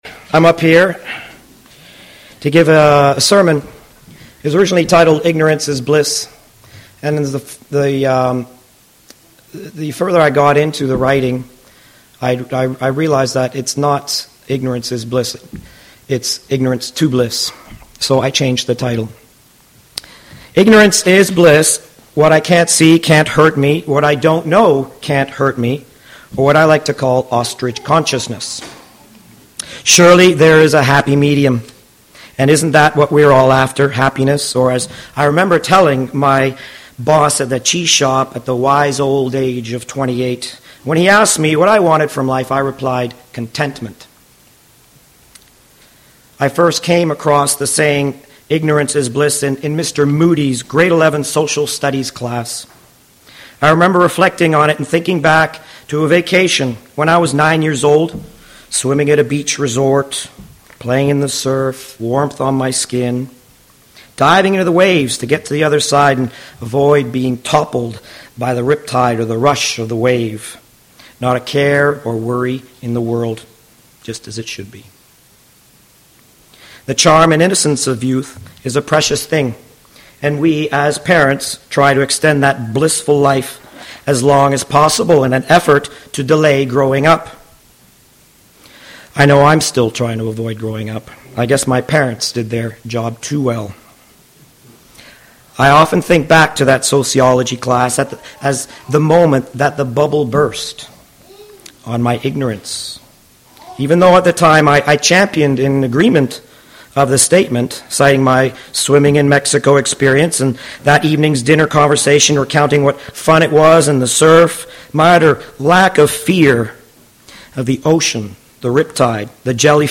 This sermon explores the idea that true happiness comes not from ignorance, but from moving from ignorance to a more engaged and connected existence.